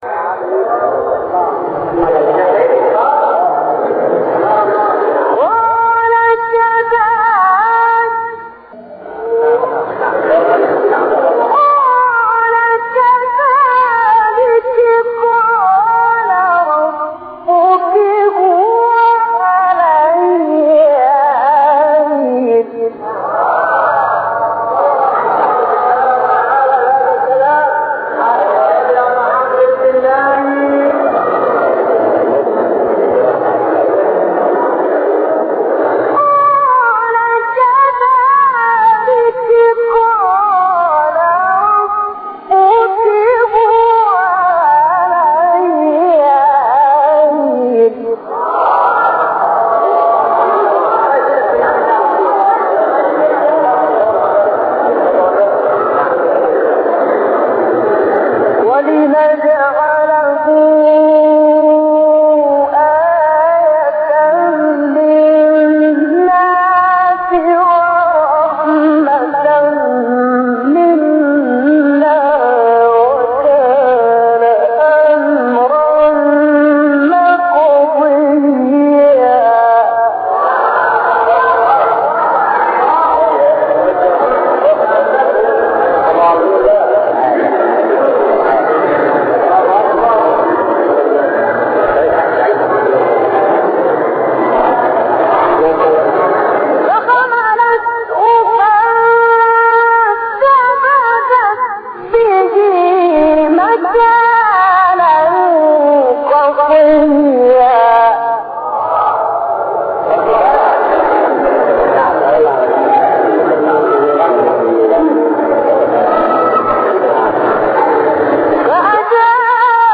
مقام الحجاز ( تلاوة )